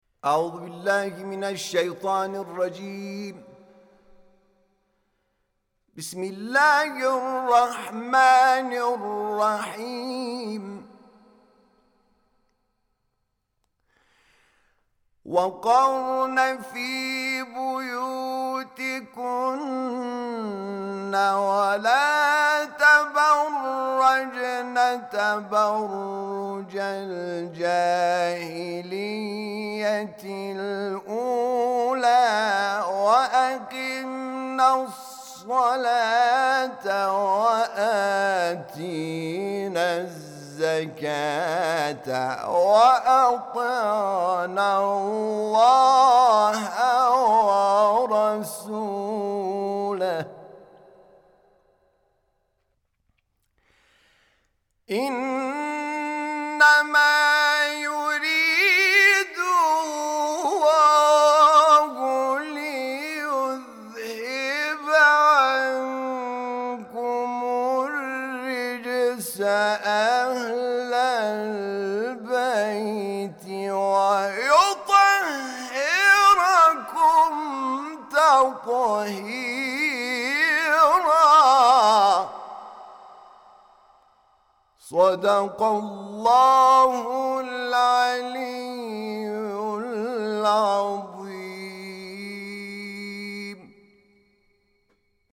تلاوت آیه ۳۳ سوره مبارکه‌ احزاب توسط حامد شاکرنژاد